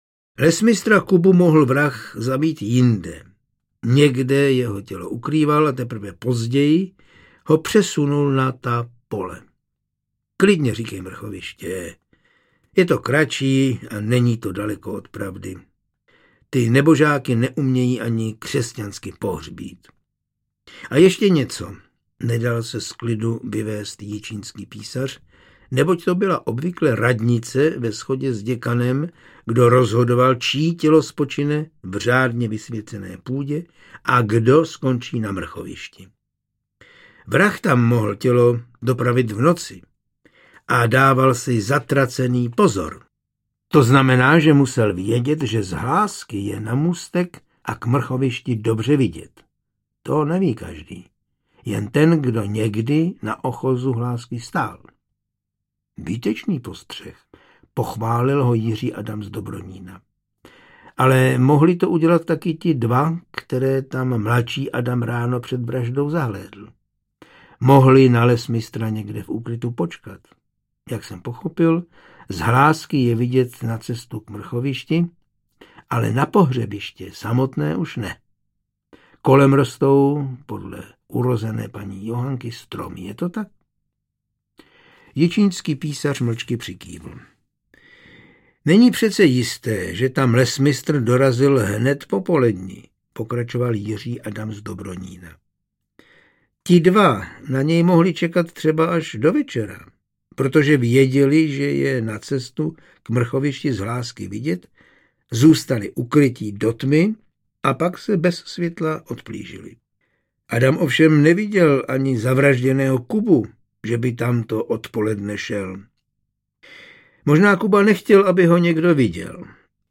Ukázka z knihy
Audiokniha je bez hudebních předělů a podkresů.